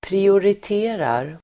Uttal: [priorit'e:rar]
prioriterar.mp3